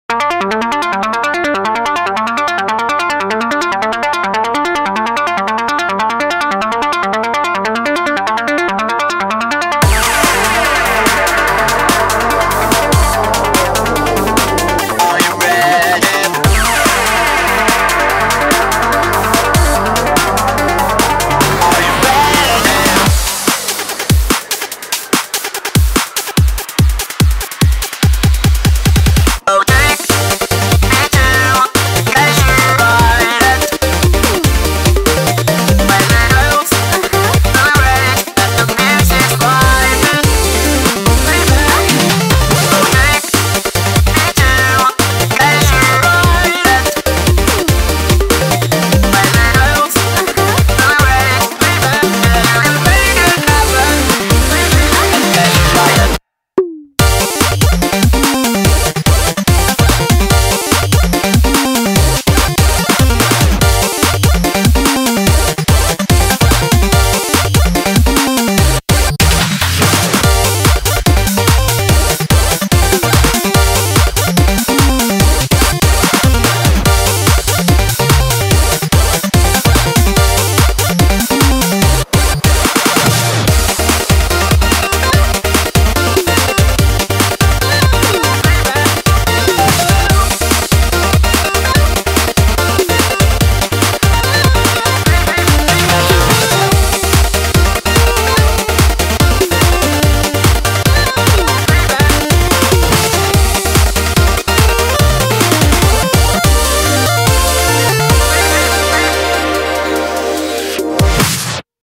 BPM145
Audio QualityPerfect (High Quality)
CommentsCHIPTUNE NU-ELECTRO